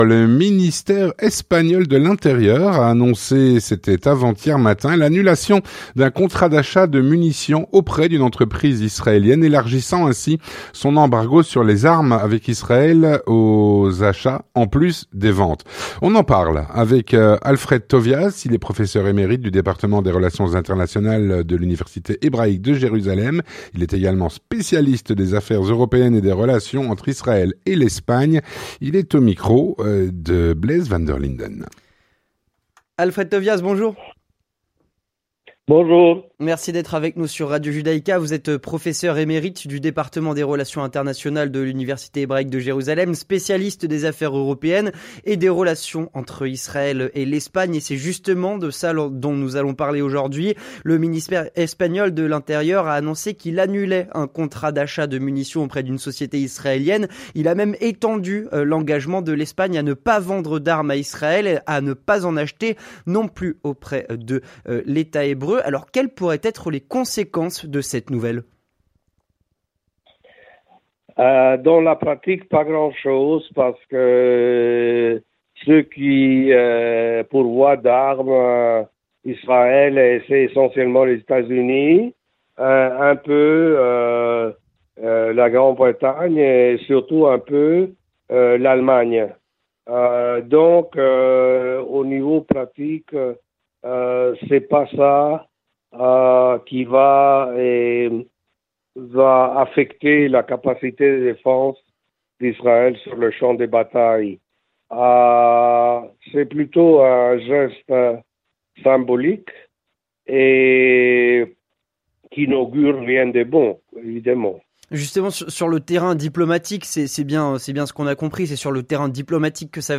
L'entretien du 18H - L'Espagne élargit son embargo sur les armes vers d'Israël à l'importation d'armes en provenance d'Israël.